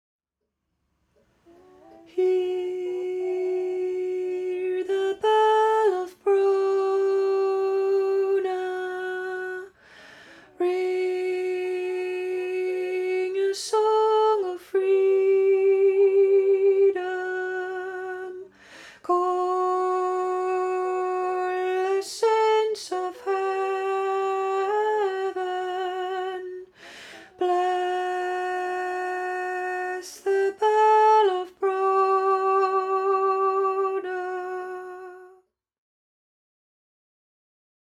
COUNTERPOINT HARMONY only